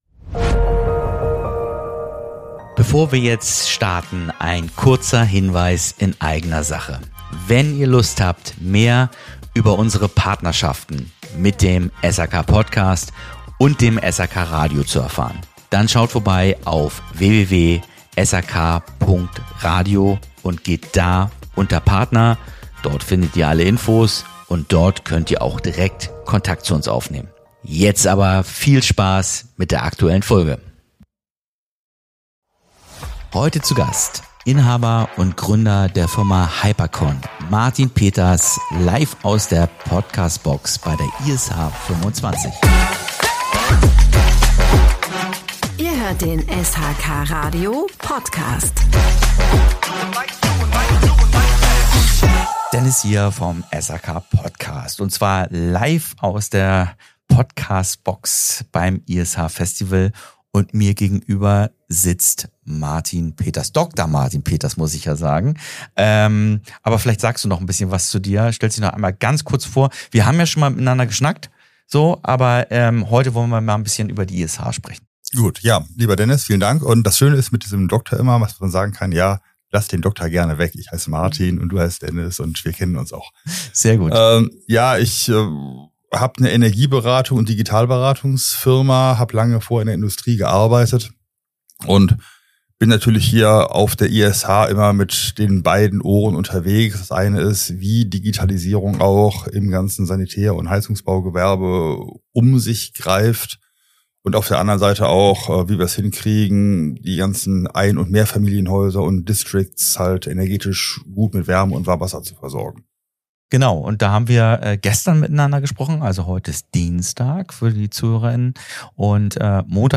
SHK Podcast #78 - Digitalisierung im SHK-Handwerk – live von der ISH 2025 ~ SHK PODCAST Podcast